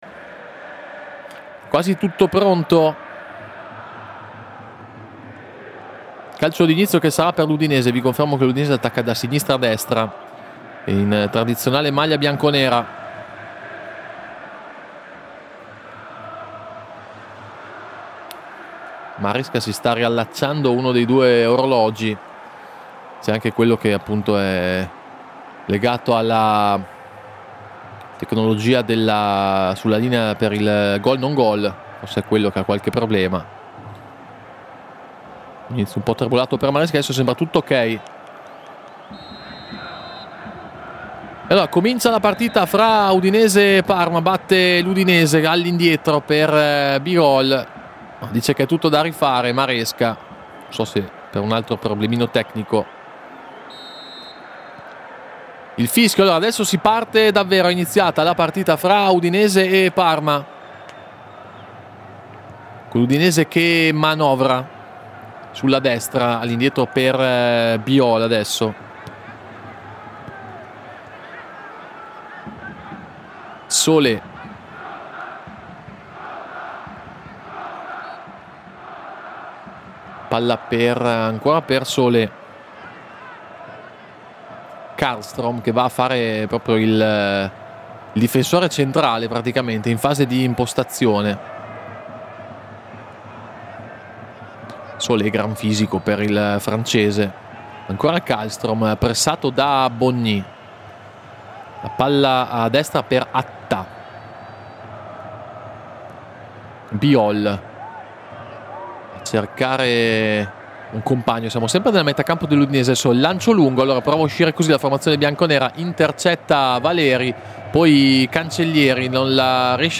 Radiocronache Parma Calcio Udinese - Parma 1° tempo - 1° marzo 2025 Mar 01 2025 | 00:48:06 1x 00:00 / 00:48:06 Subscribe Share RSS Feed Share Link Embed